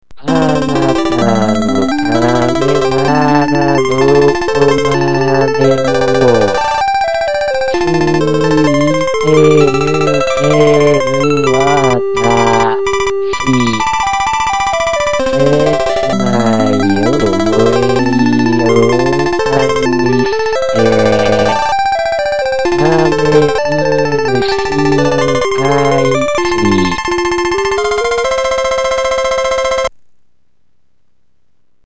たいそう気持ち悪いものが出来上がりました。